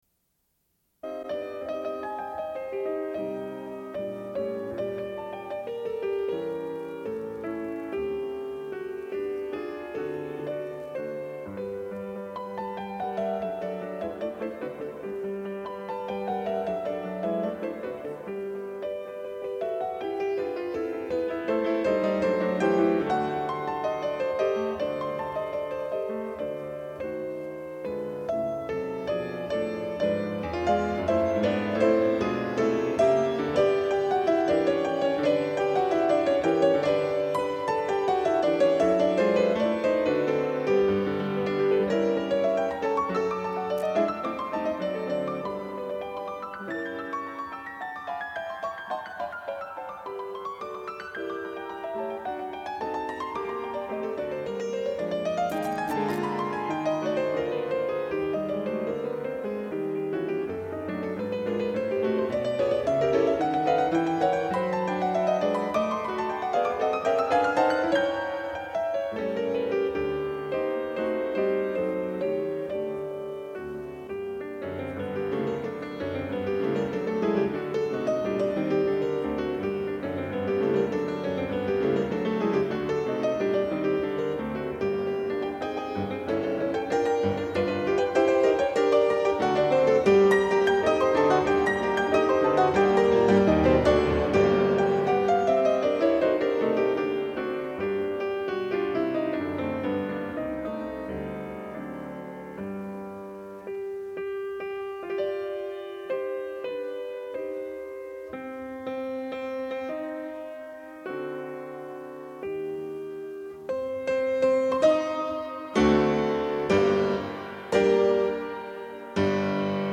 Une cassette audio, face B29:28